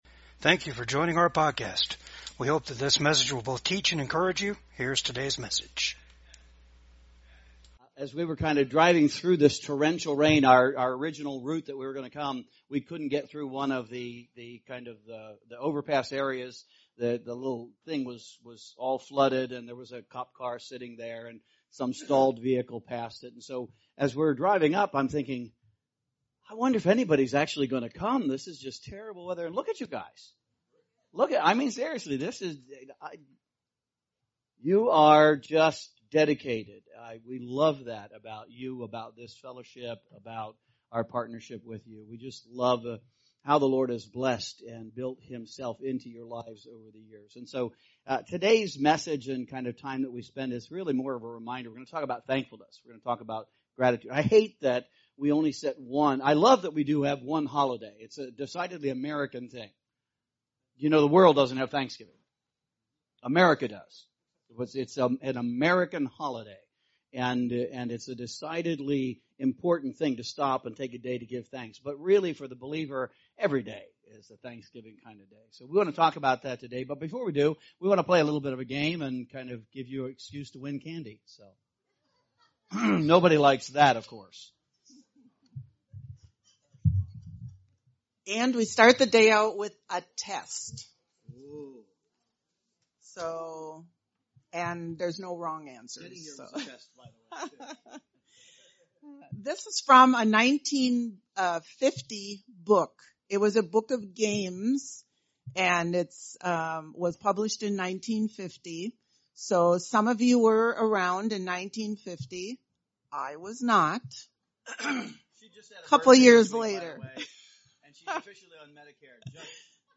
Romans 1 Service Type: VCAG WEDNESDAY SERVICE THANKFULNESS AND GRATITUDE SHOULD BECOME OUR ATTITUDE.